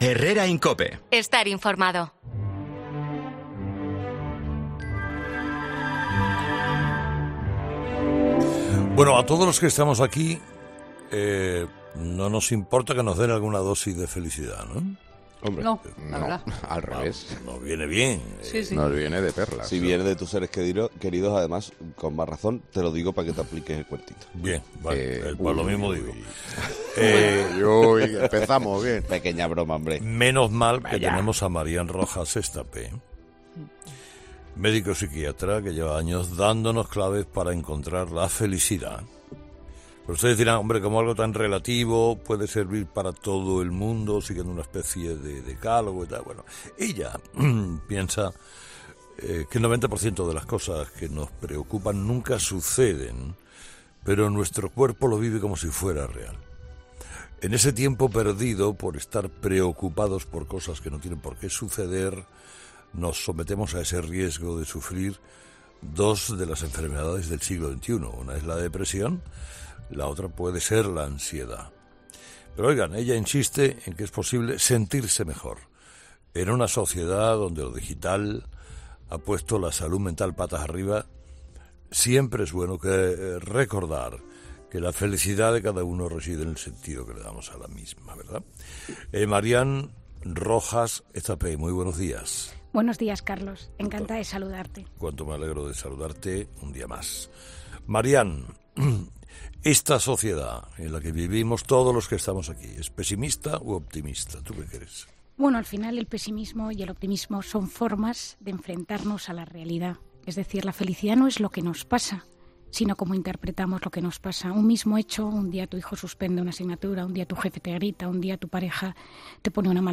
La psiquiatra se ha pasado por 'Herrera en COPE' para contar las claves de la felicidad